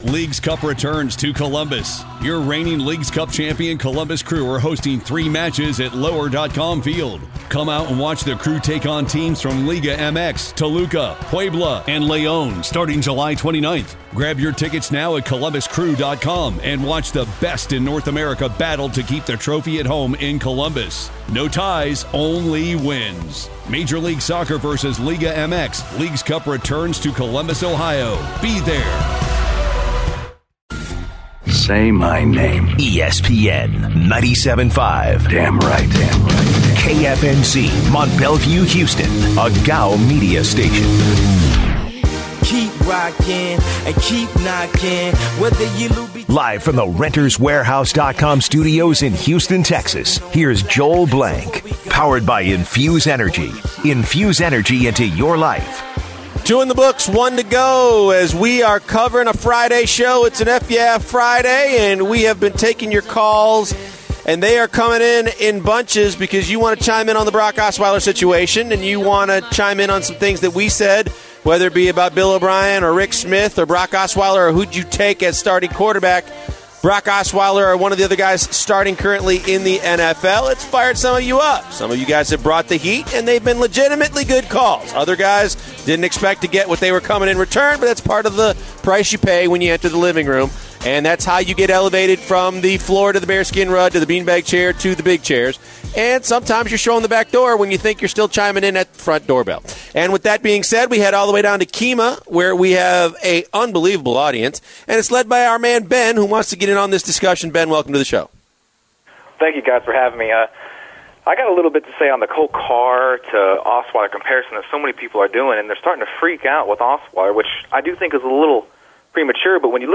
In the final hour, They continue their talks on the Houston Texans and the next game against the colts. They also take calls.